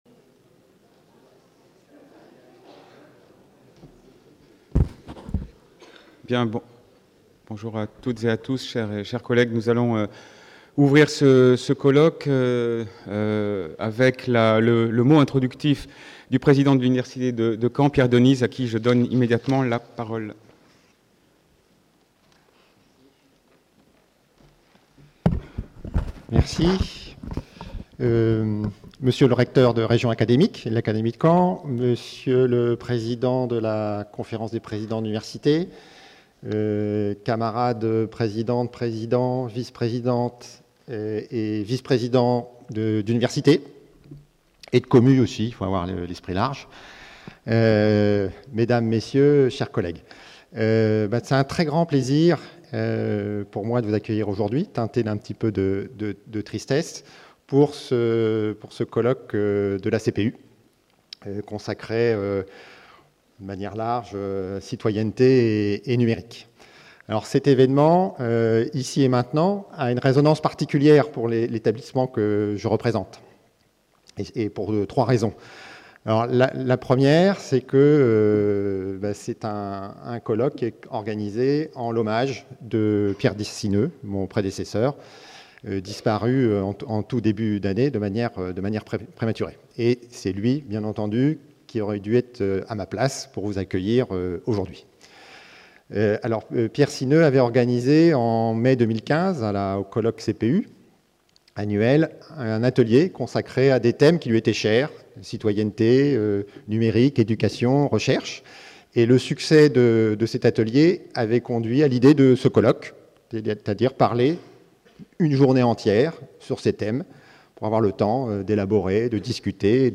01 - Accueil et ouverture du colloque (cpucaen 2016) | Canal U
Colloque de La Conférence des présidents d’université (CPU) Université de Caen Normandie 30 novembre 2016 Devenir citoyen à l’ère du numérique : enjeux scientifiques et éducatifs Accueil du colloque par Pierre Denise, président de l’université de Caen-Normandie Ouverture du Colloque par Jean-Loup Salzmann, président de la CPU